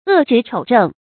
惡直丑正 注音： ㄨˋ ㄓㄧˊ ㄔㄡˇ ㄓㄥˋ 讀音讀法： 意思解釋： 嫉害正直的人。